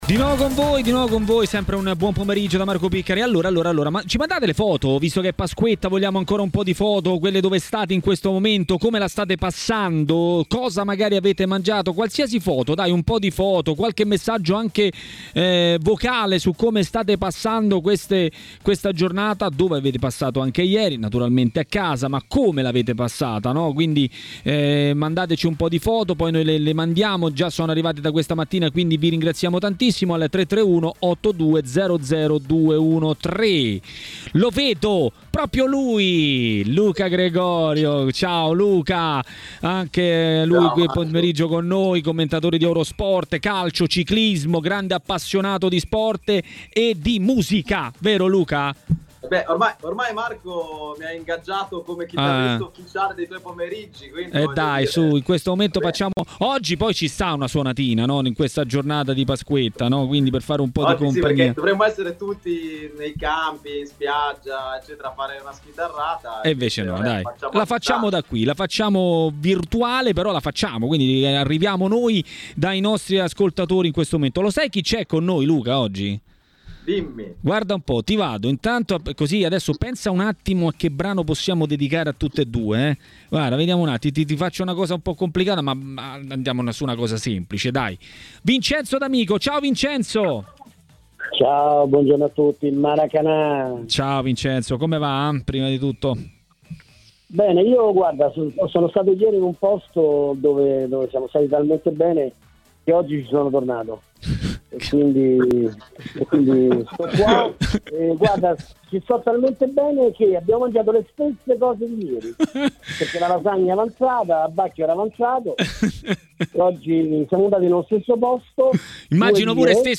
Vincenzo D'Amico, ex calciatore e opinionista tv, ha commentato così le notizie del giorno a Maracanà, trasmissione di TMW Radio.